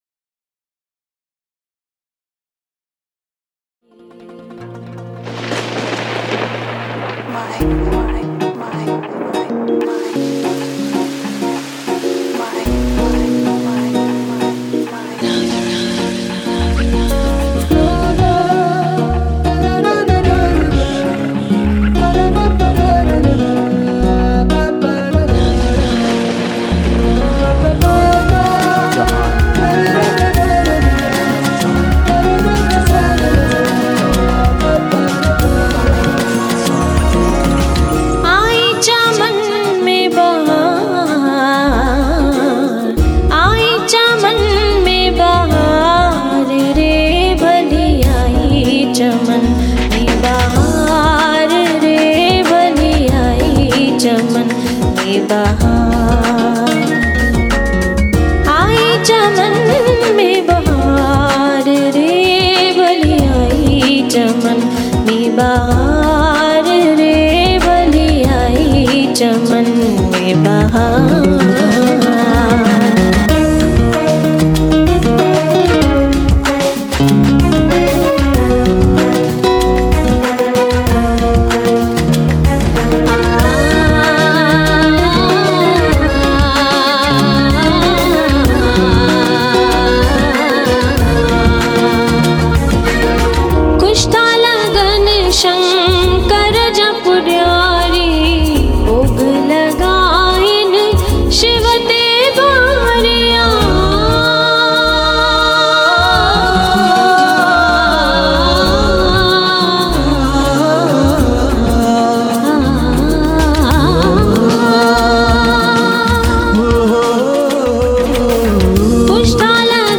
old and new Sindhi songs